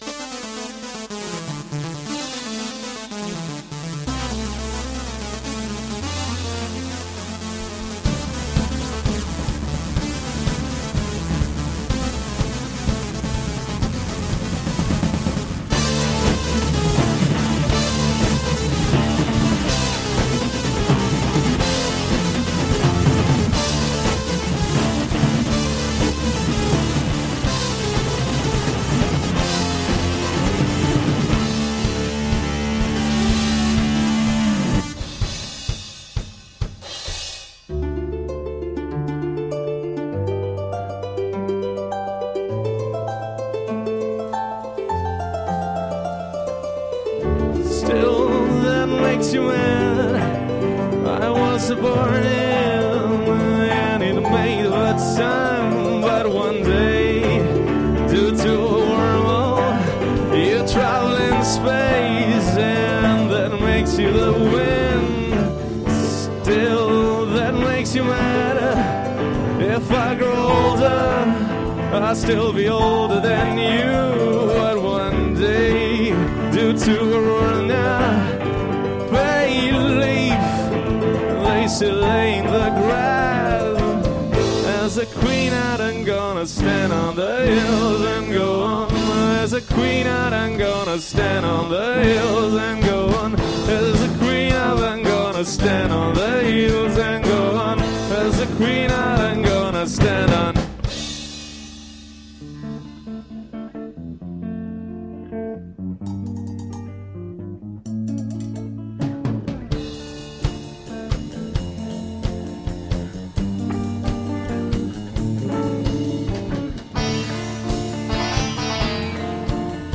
Luogo esecuzioneCalderino Rock Festival
GenereMusica Sperimentale di Confine